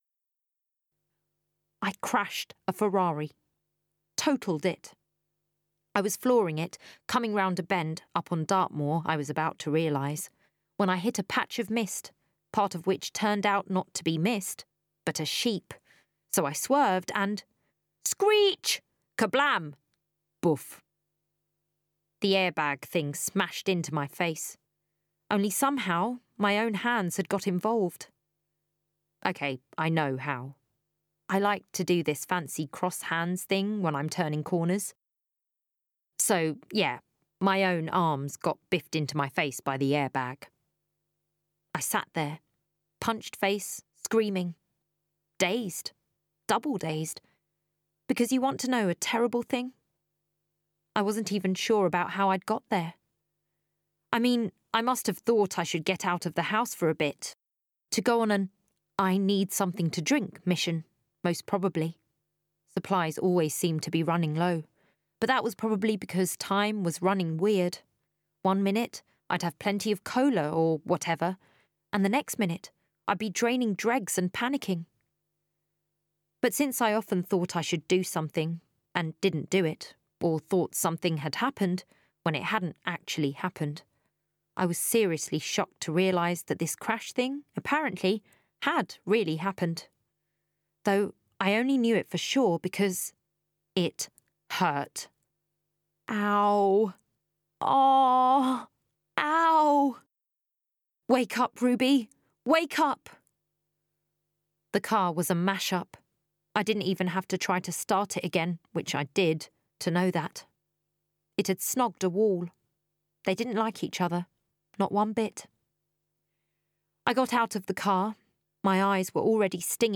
THE STORM - UK AUDIOBOOK